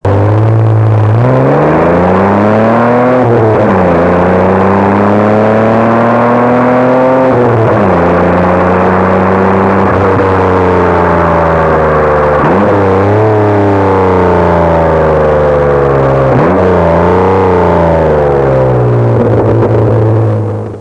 Check out this 20 second mp3 of the Hyena Records Commodore in action. 60 in 1st, 100 in 2nd, 120ish in 3rd